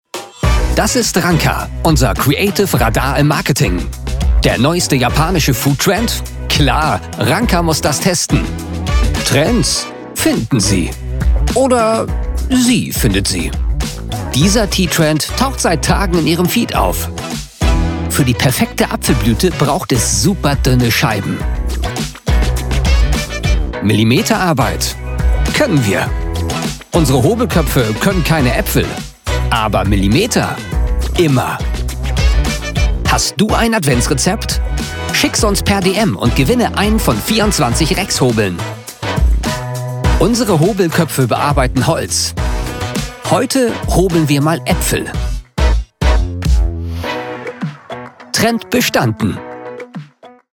markant, plakativ
Mittel plus (35-65)
Norddeutsch
Eigene Sprecherkabine
Commercial (Werbung)